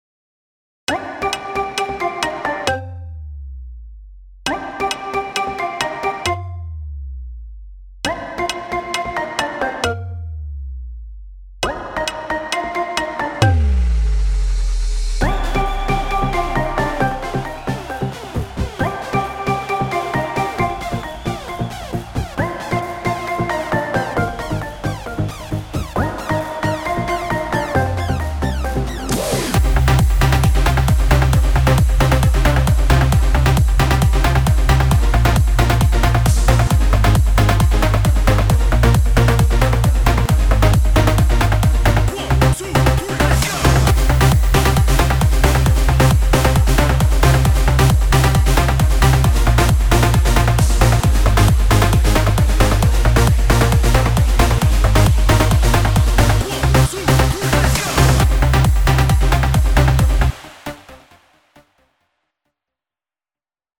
וכמה מקצבים חדשים עם כמה שיטות חדשות שלמדתי